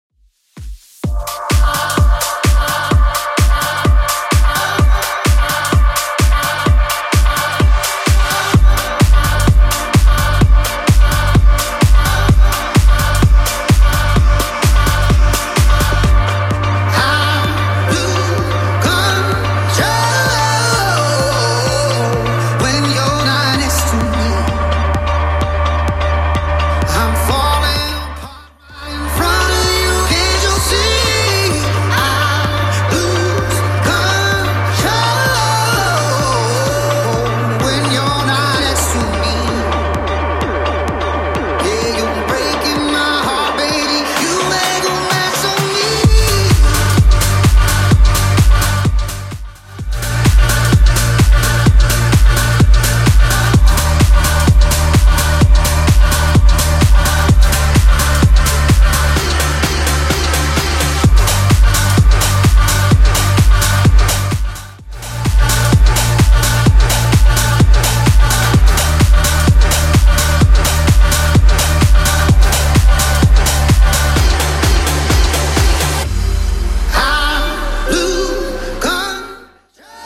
Genre: 80's Version: Clean BPM: 143